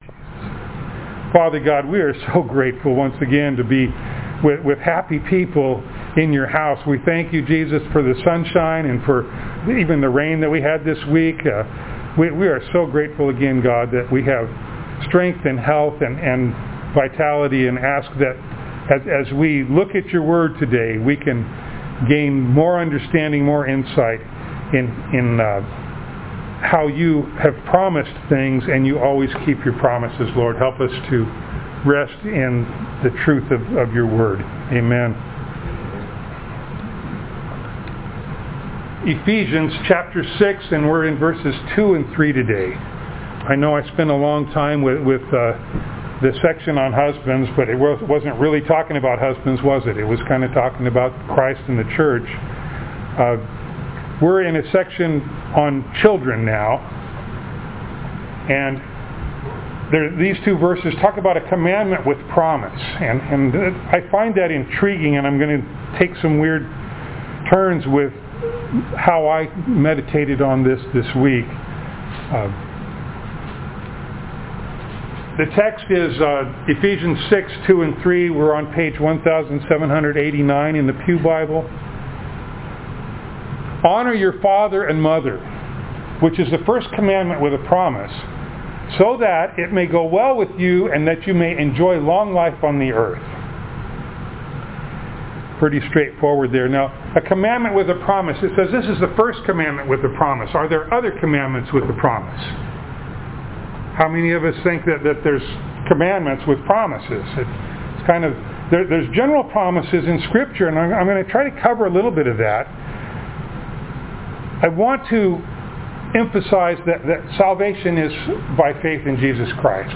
Ephesians 6:2-3 Service Type: Sunday Morning Download Files Notes « Obey in the Lord Choice